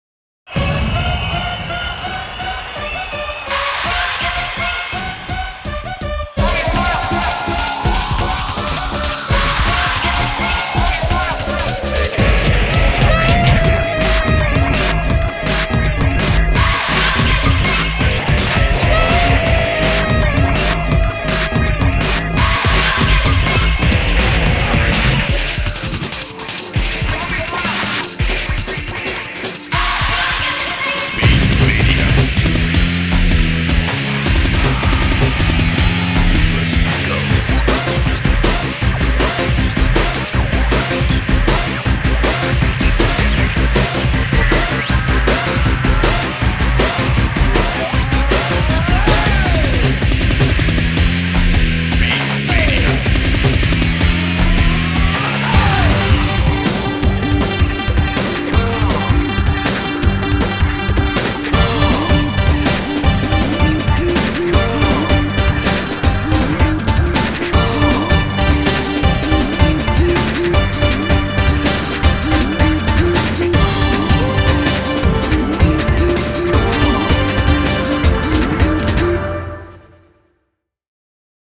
BPM158-165
Audio QualityPerfect (High Quality)
Genre: MIXTURAL.